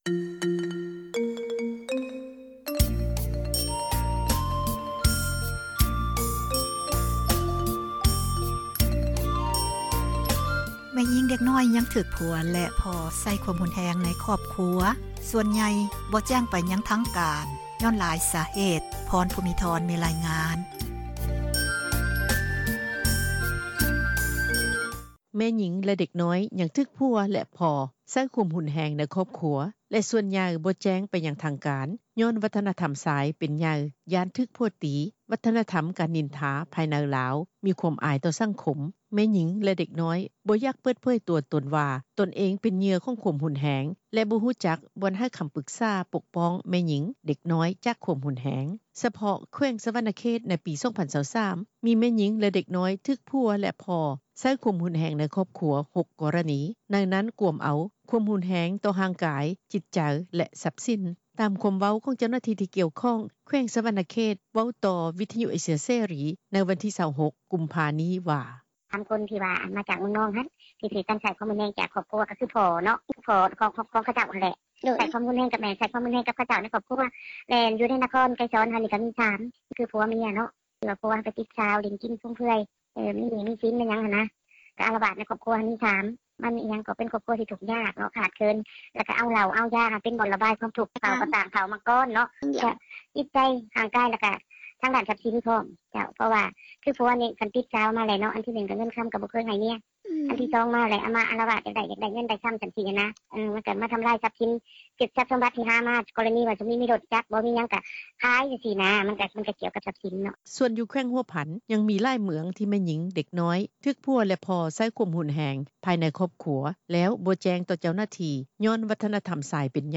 ສະເພາະ ແຂວງສວັນນະເຂດ ໃນປີ 2023 ມີແມ່ຍິງ ແລະ ເດັກນ້ອຍ ຖືກຜົວ ແລະພໍ່ ໃຊ້ຄວາມຮຸນແຮງໃນຄອບຄົວ 6 ກໍຣະນີ ໃນນັ້ນ ກວມເອົາຄວາມຮຸນແຮງຕໍ່ຮ່າງກາຍ, ຈິດໃຈ ແລະ ຊັພສິນ. ຕາມຄວາມເວົ້າຂອງ ເຈົ້າໜ້າທີ່ ທີ່ກ່ຽວຂ້ອງ ແຂວງສວັນນະເຂດ ເວົ້າຕໍ່ວິທຍຸ ເອເຊັຍເສຣີ ໃນວັນທີ 26 ກຸມພາ ນີ້ວ່າ:
ດັ່ງ ເຈົ້າໜ້າທີ່ ທີ່ກ່ຽວຂ້ອງ ແຂວງຫົວພັນ ກ່າວໃນມື້ດຽວກັນນີ້ວ່າ: